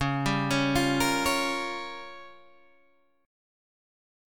C# 6th Flat 5th